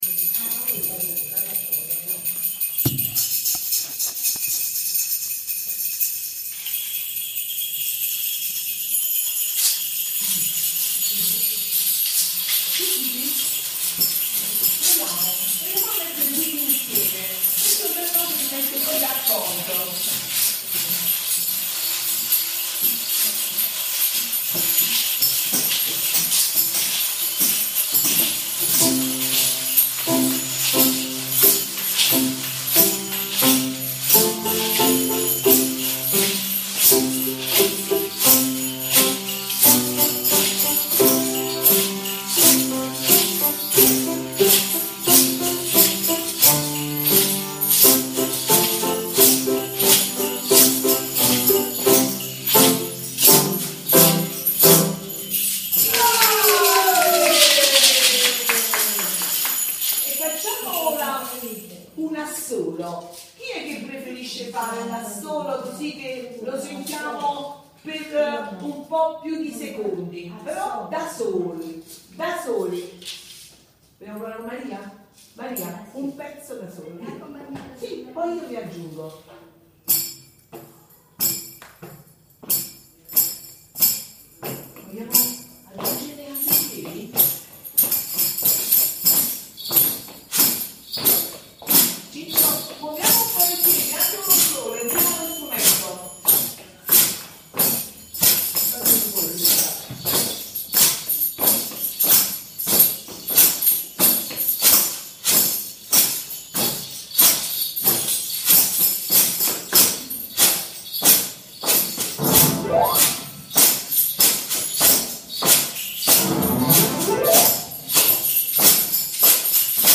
ci siamo dedicati all’esplorazione sonora di materiali e strumenti con materiale di riciclo
esplorazione strumenti musicali evergreen
le-anime-con-gli-strumenti-dialogano.mp3